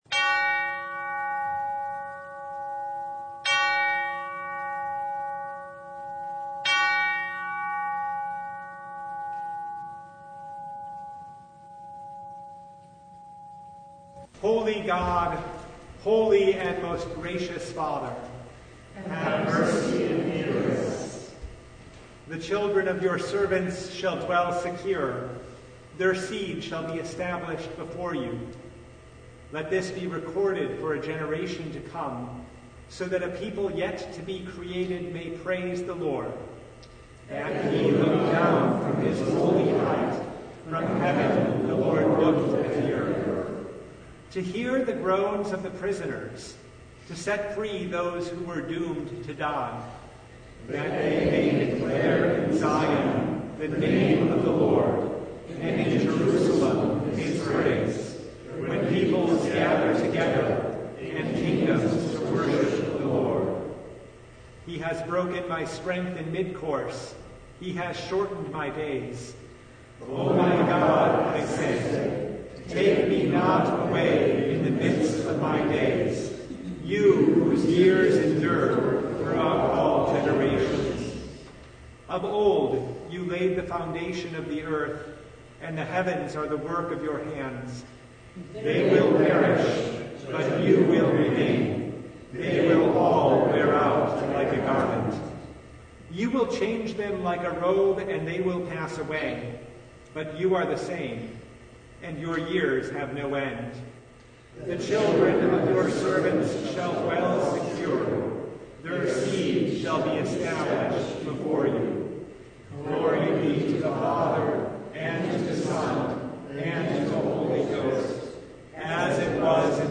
The Third Advent Noon Service (2022)